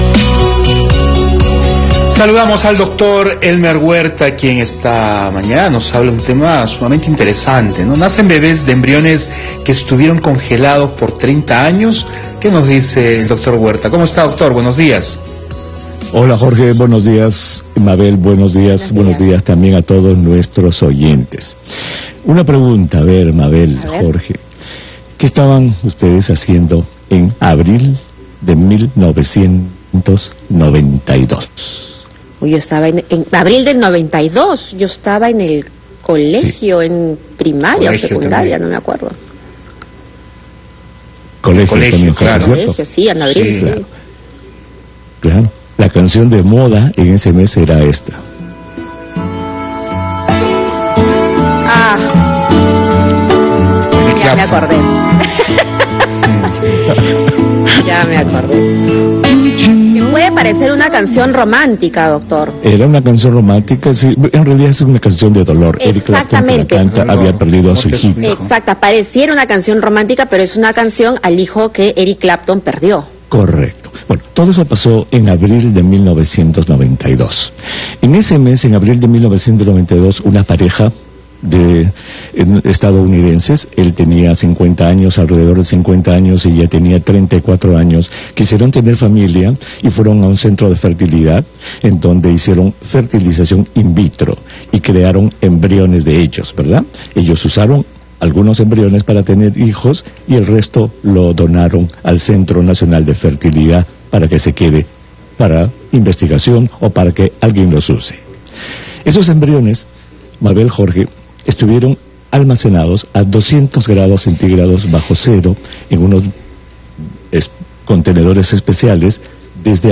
El doctor Elmer Huerta comentó acerca del nacimiento de embriones que estuvieron congelados por 30 años.